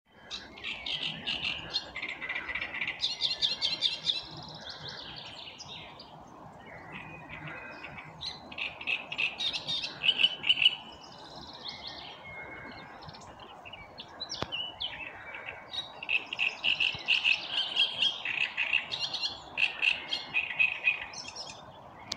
Niedru strazds, Acrocephalus arundinaceus
Administratīvā teritorijaBabītes novads
StatussDzied ligzdošanai piemērotā biotopā (D)